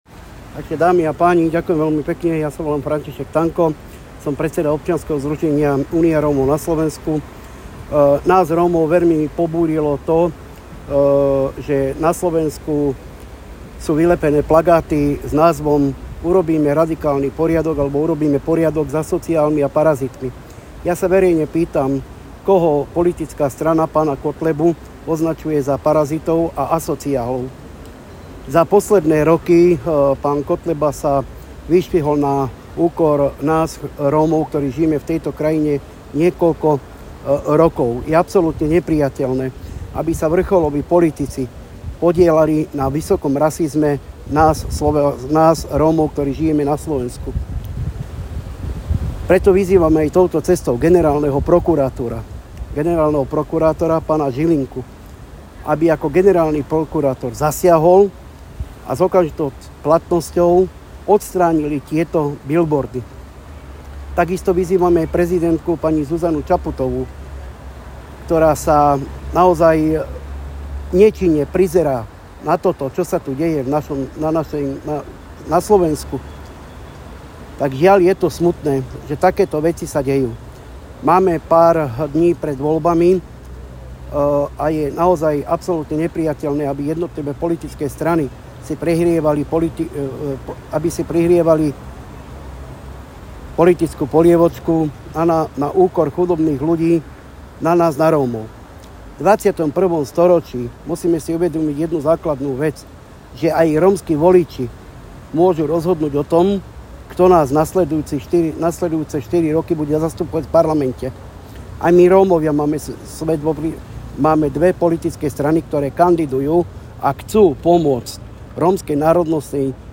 na tlačovej besede